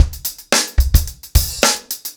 TupidCow-110BPM.33.wav